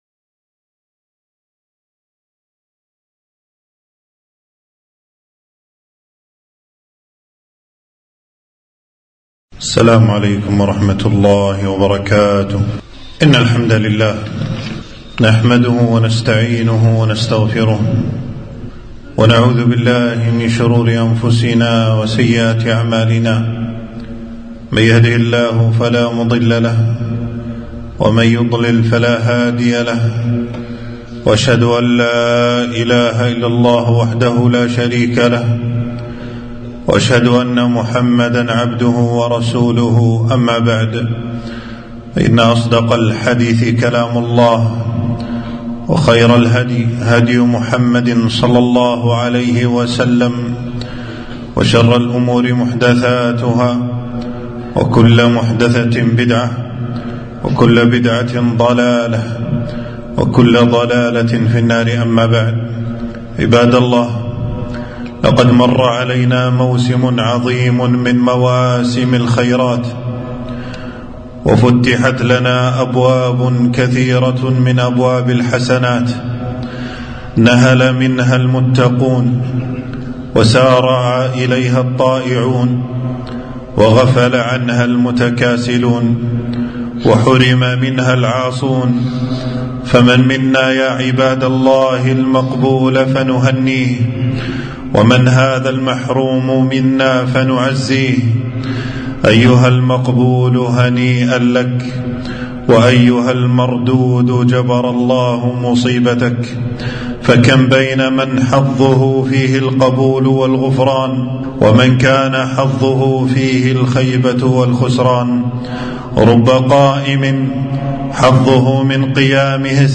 خطبة - الثبات الثبات بعد شهر الطاعات